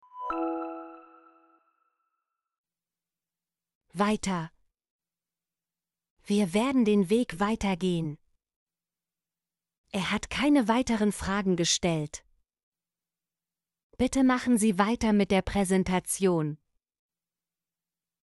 weiter - Example Sentences & Pronunciation, German Frequency List